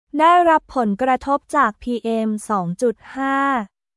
ได้รับผลกระทบจาก PM2.5　ダイラップ・ポンガトップ・ジャーク PM2.5